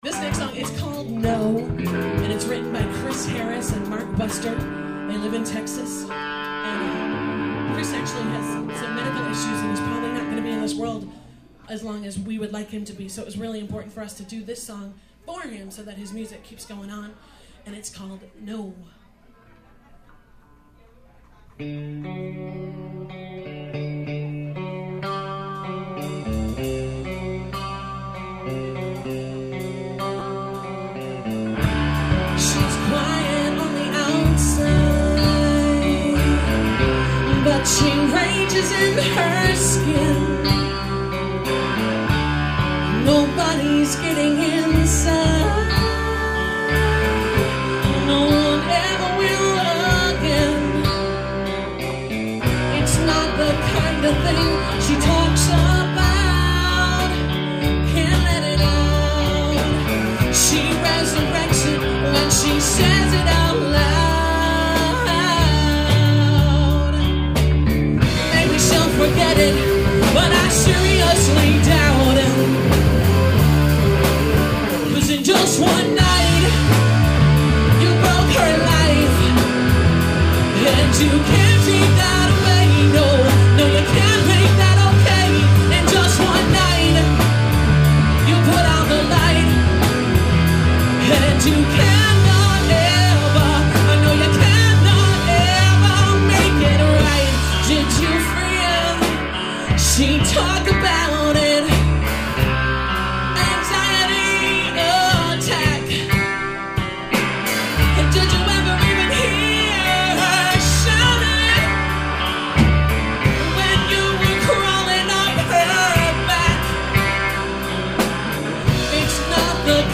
recorded live at Murphy's Law in South Boston